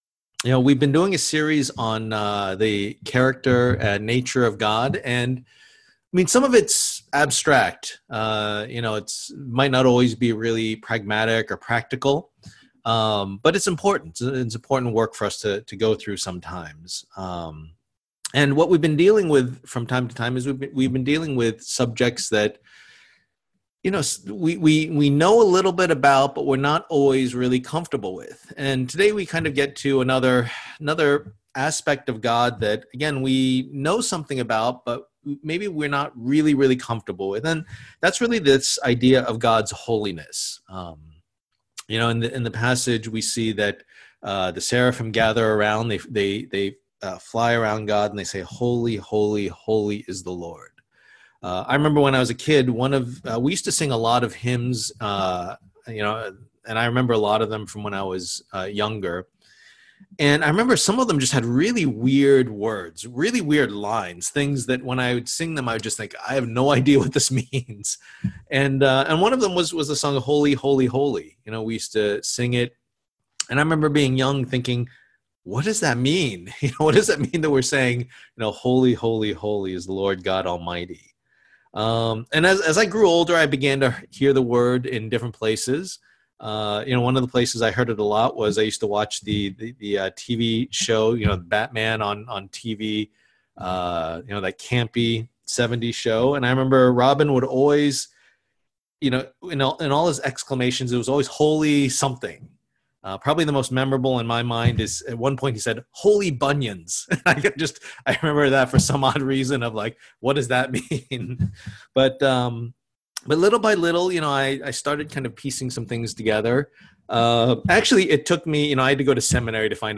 Passage: Isaiah 6:1-13 Service Type: Lord's Day